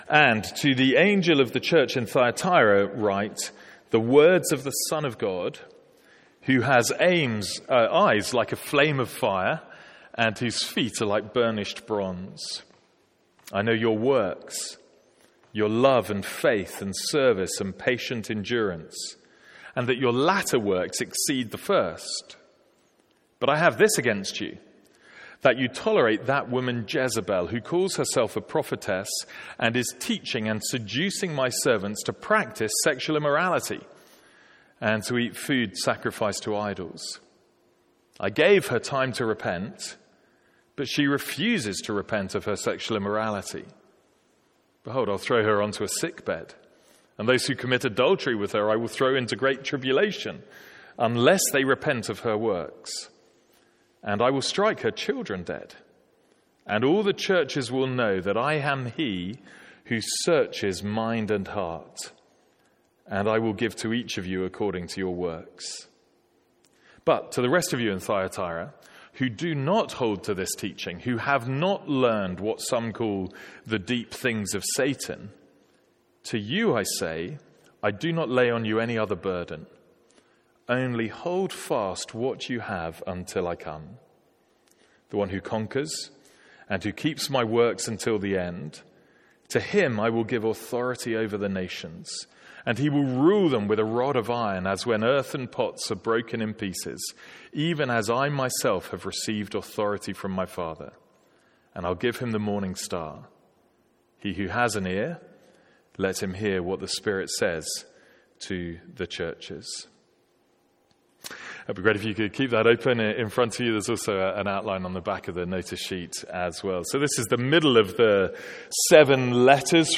Sermons | St Andrews Free Church
From our evening series in the book of Revelation.